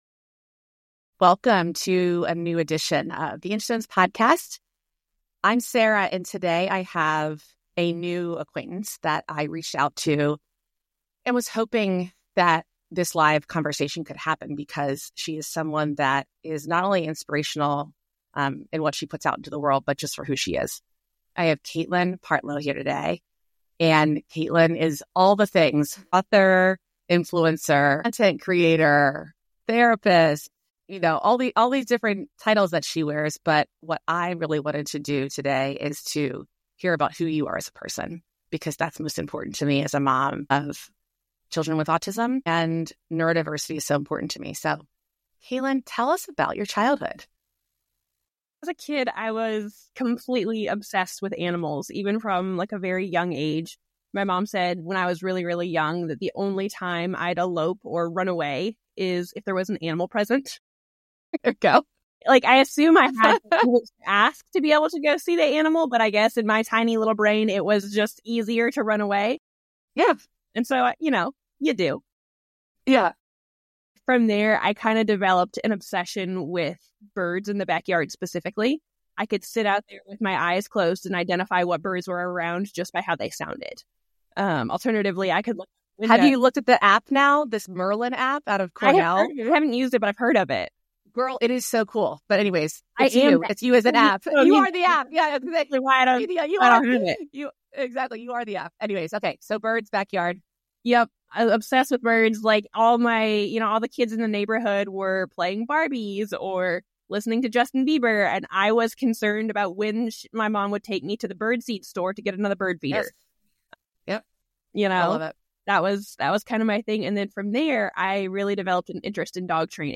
Supporting Neurodivergent Kids: A Conversation with Love on the Spectrum's Kaelynn Partlow